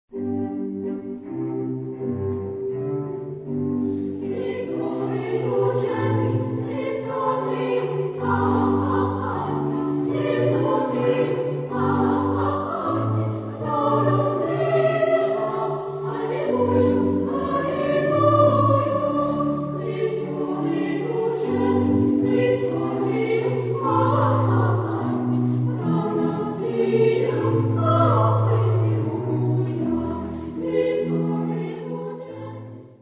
Genre-Stil-Form: geistlich ; Barock ; Motette ; Antiphon
Chorgattung: SS  (2 Kinderchor ODER Frauenchor Stimmen )
Instrumente: Orgel (1) oder Klavier (1)
Tonart(en): A-Dur